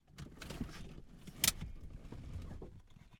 Pontiac_Trans_Am_84_t12_Var_SFX_Seatbelt_Interior_AMBEO1.ogg